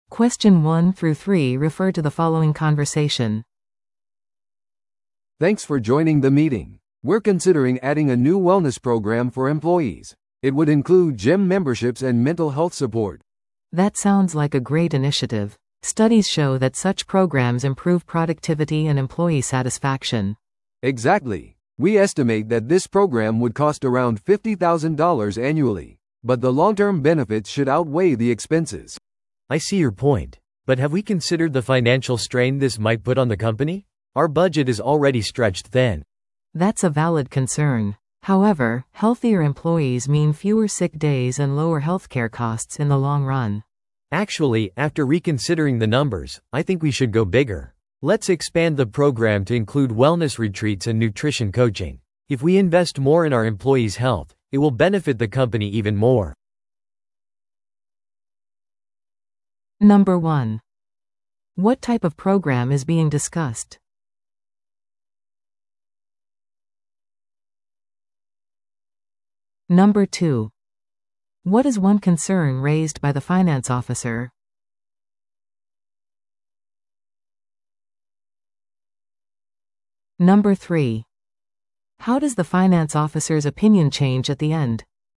会社の福利厚生プログラムの会議